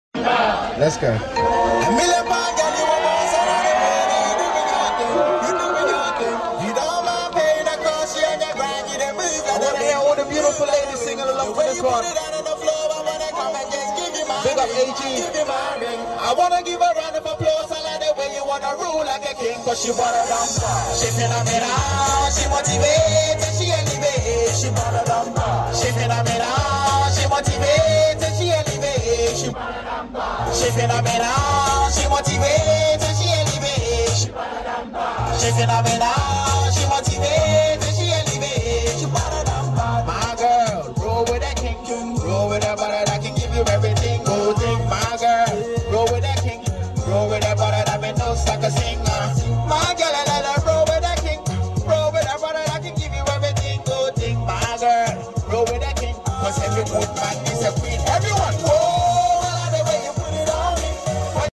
Live at kings cross